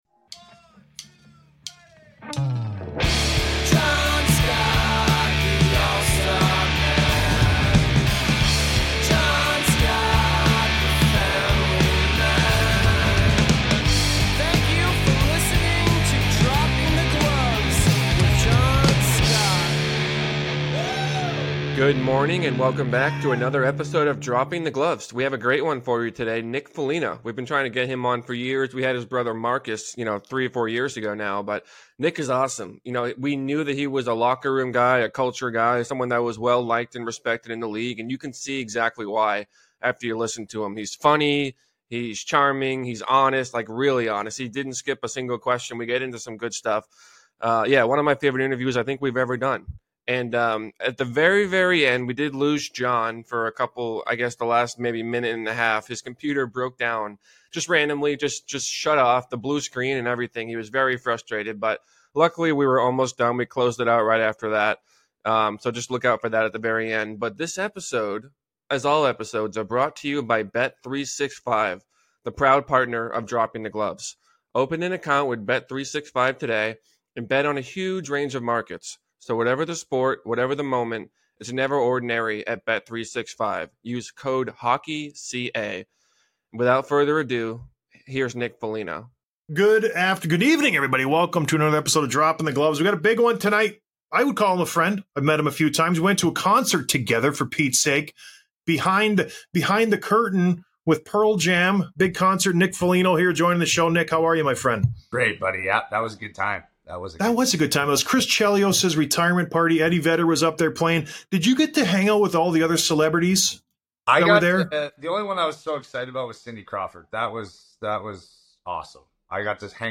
Interview with Nick Foligno, Chicago Blackhawks